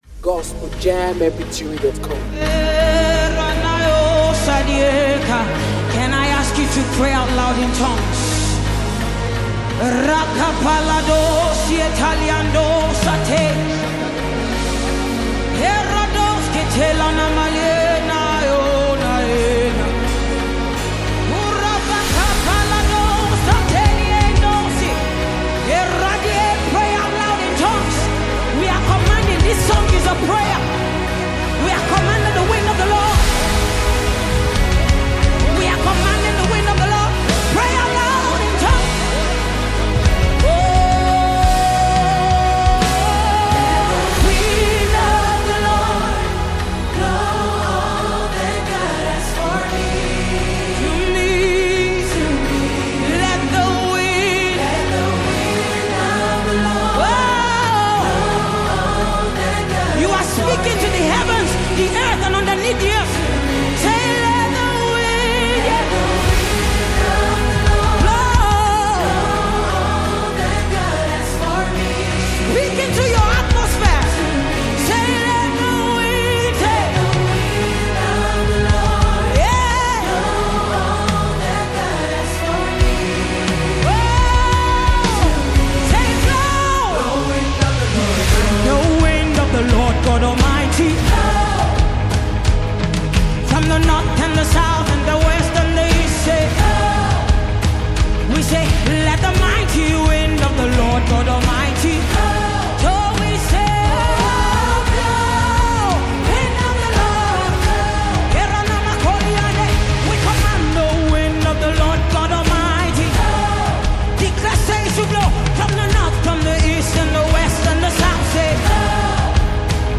worship song
gospel